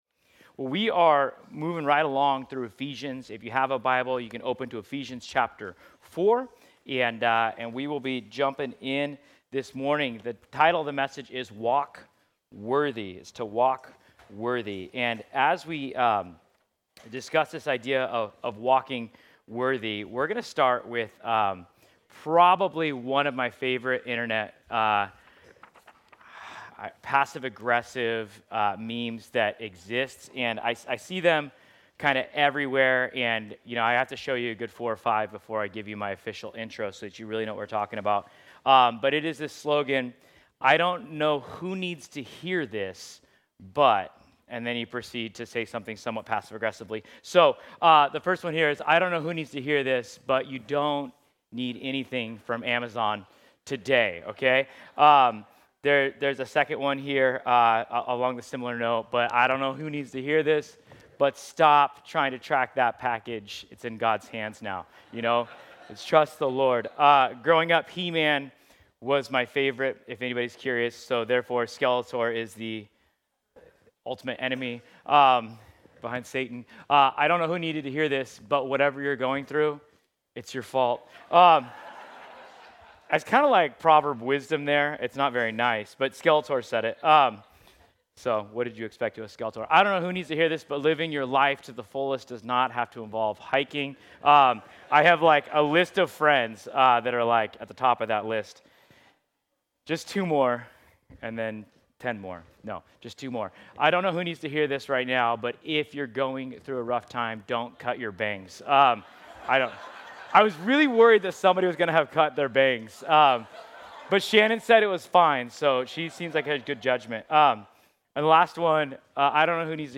Ephesians 4:1-6 Service Type: Sunday This week